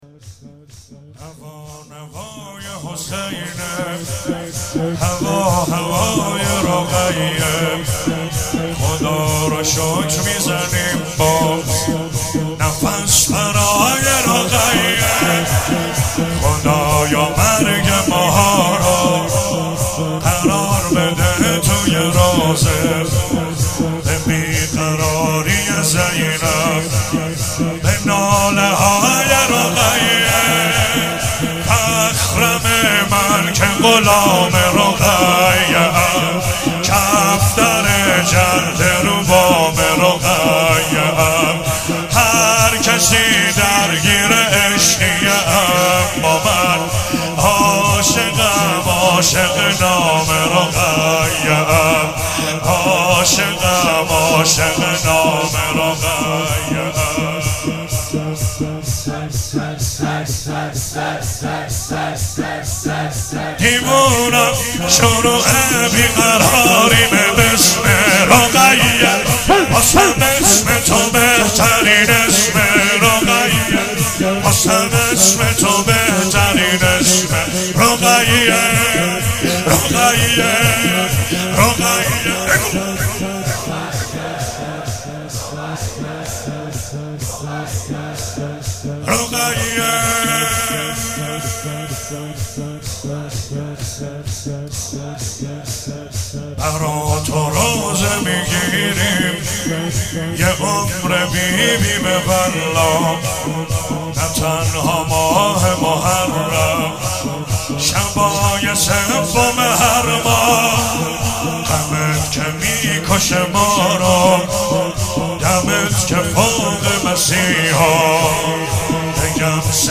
مناسبت : شب سوم محرم
قالب : شور